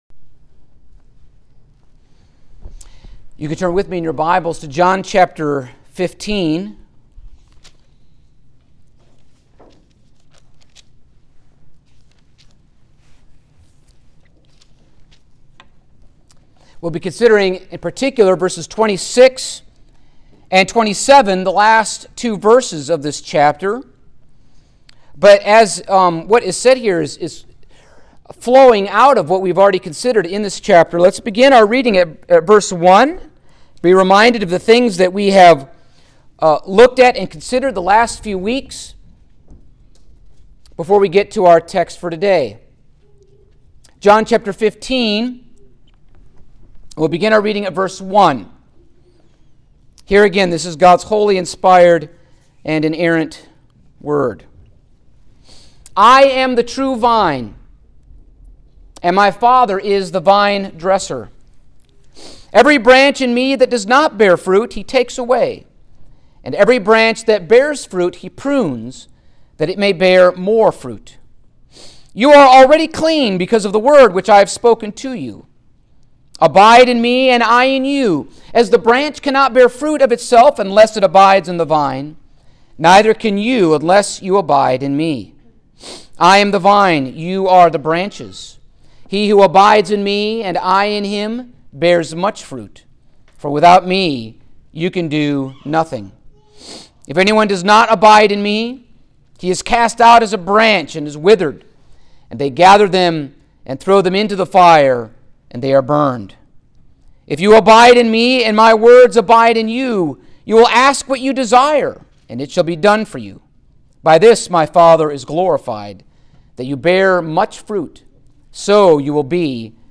Passage: John 15:26-27 Service Type: Sunday Morning